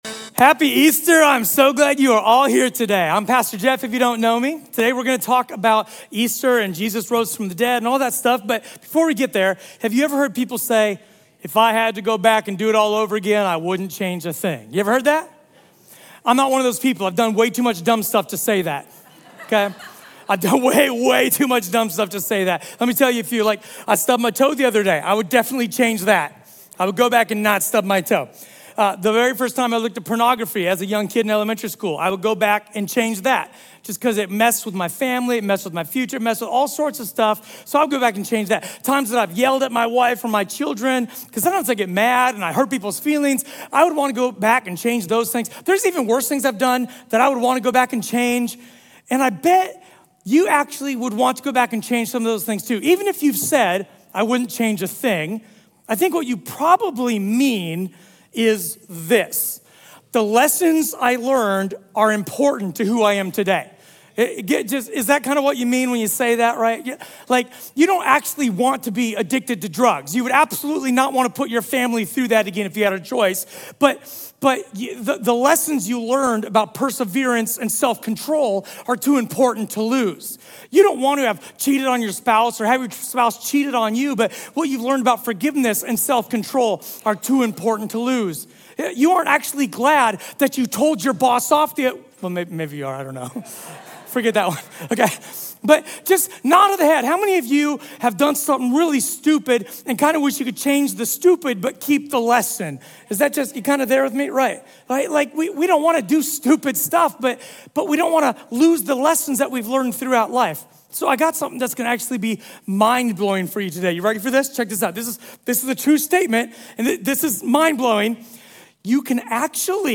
A sermon from the series "Revolution Sermon."